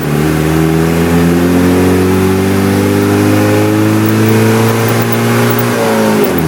Index of /server/sound/vehicles/lwcars/uaz_452